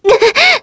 Lewsia_A-Vox_Damage_kr_01.wav